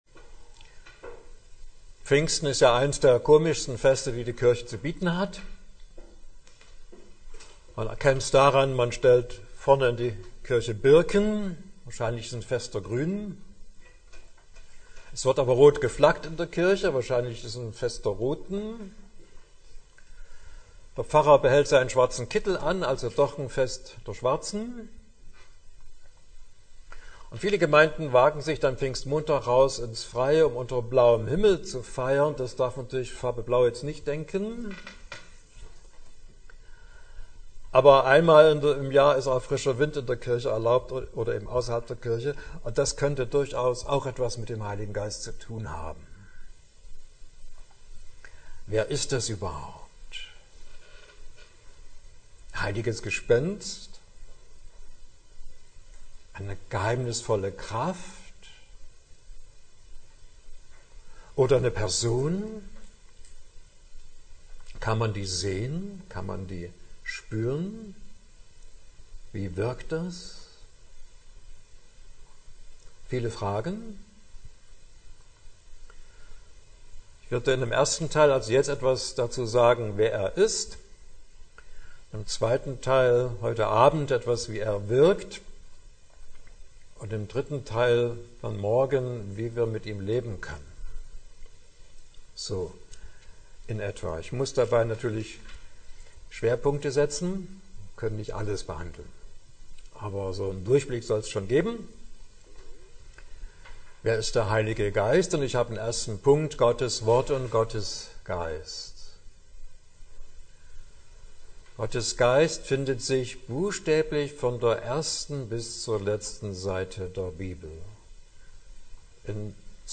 Mai 2024 Heruntergeladen 617 Mal Kategorie Audiodateien Vorträge Schlagwörter Der Heilige Geist , Heiliger Geist , Pfingsten Beschreibung: Der Heilige Geist: Wer Er ist Vortrag ca. 46 Minuten